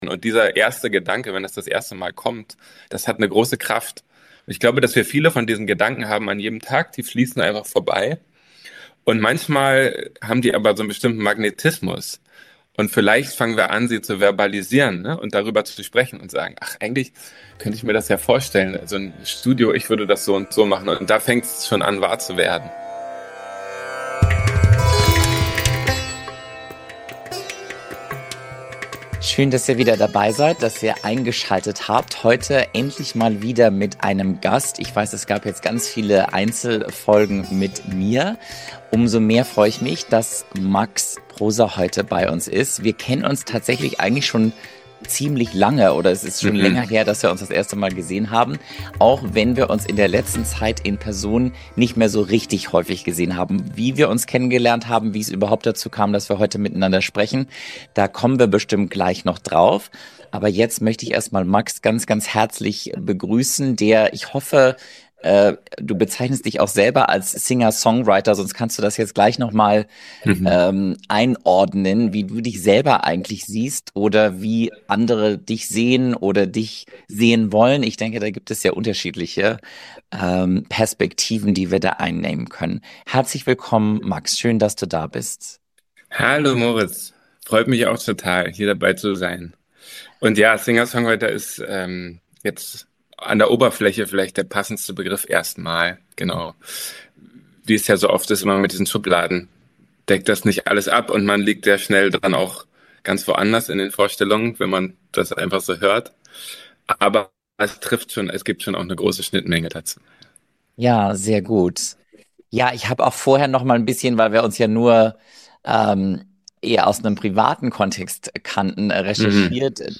In dieser Episode habe ich den bekannten Singer-Songwriter Max Prosa zu Gast. Gemeinsam tauchen wir tief in die Welt der Musik und Dichtkunst ein und sprechen darüber, welche kreativen Prozesse und Inspirationen hinter Max' Liedern und Gedichten stecken.